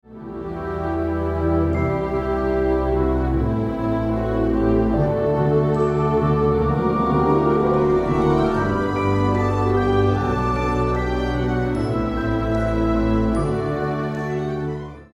Hence, an orchestral palette is utilised with keen focus on specific chord structures that mimic tunes from films such as Cinderella (1950), Peter Pan (1953), and Lady and the Tramp (1955).